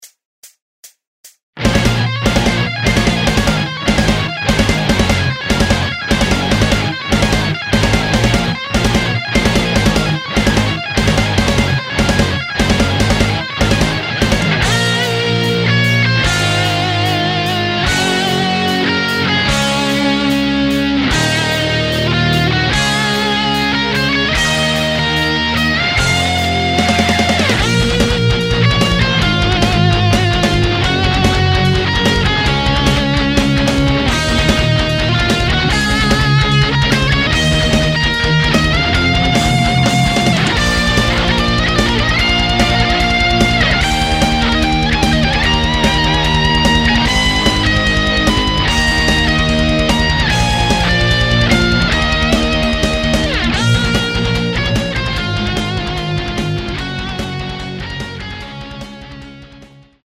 메탈 편곡